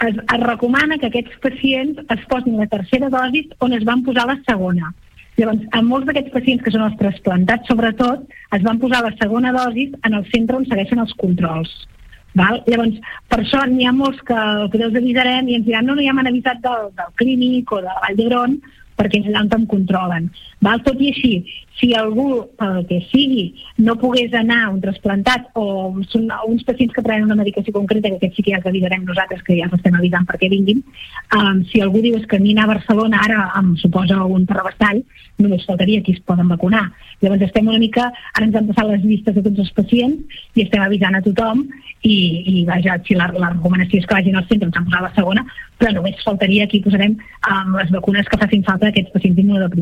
Entrevistes SupermatíSupermatí